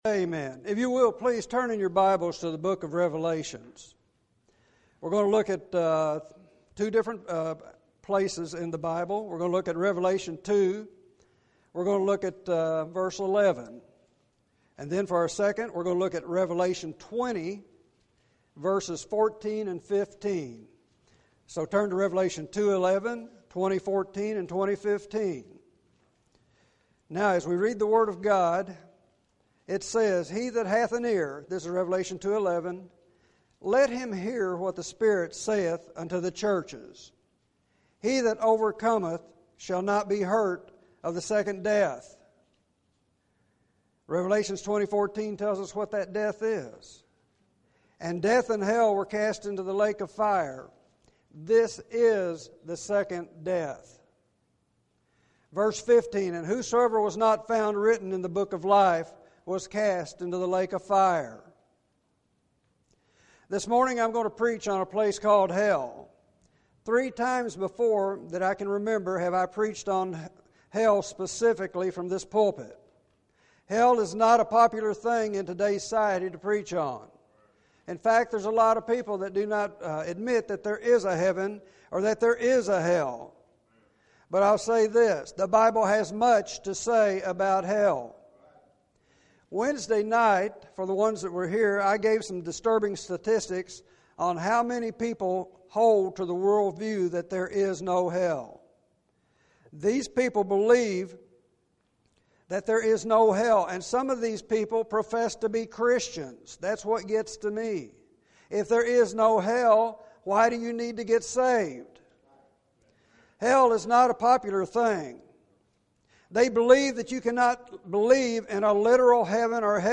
A Place Called Hell Series: Calvary Baptist Church Date: December 15, 2017 Download: A Place Called Hell Plays: 3 Share this sermon: ?